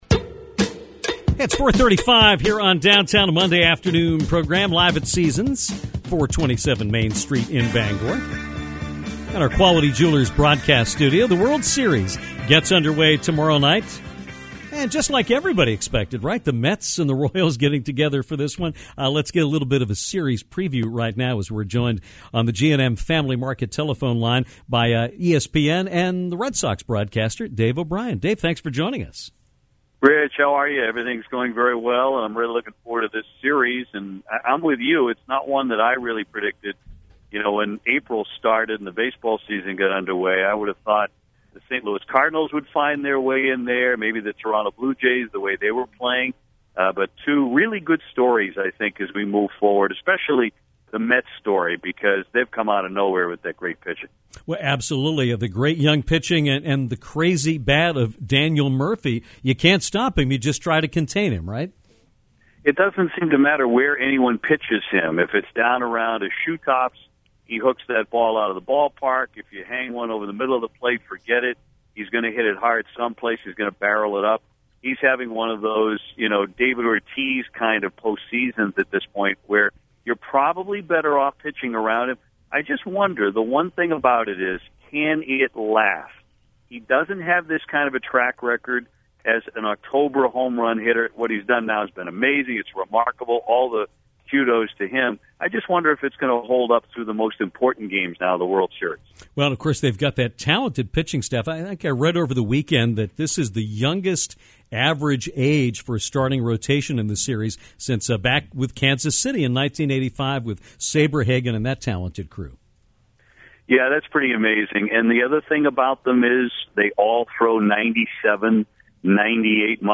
Dave O’Brien, ESPN play by play voice and the new TV voice of the Red Sox for NESN, joined Downtown to talk about the upcoming World Series between the New York Mets and the Kansas City Royals.